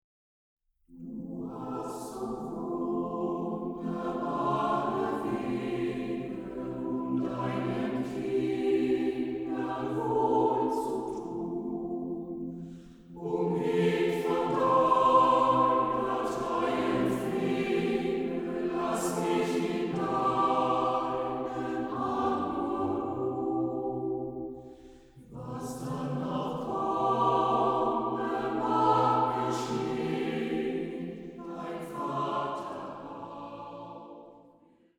gemischter Chor, Oboe